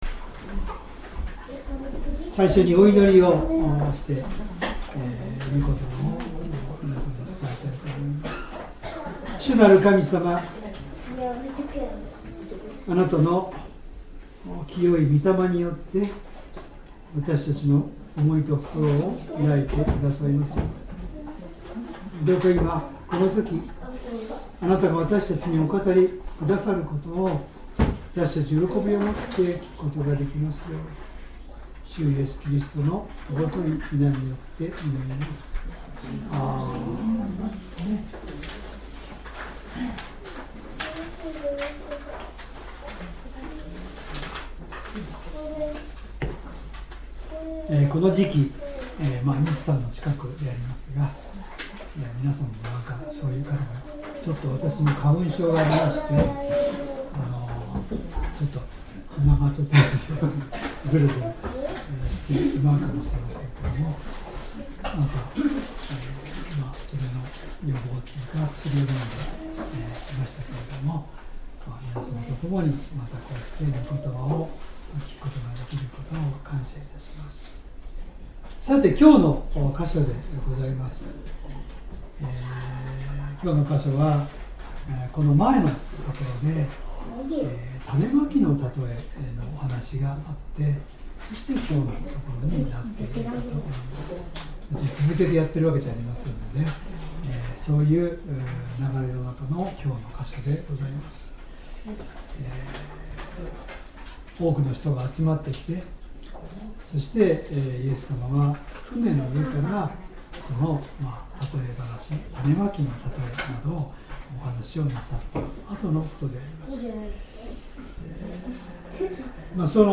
Sermon
2025年 3月8日 主日礼拝 説教 どうして怖がるのですか マルコの福音書4章35-41 4:35 さてその日、夕方になって、イエスは弟子たちに「向こう岸へ渡ろう」と言われた。